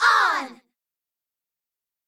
okItsOngirls5.ogg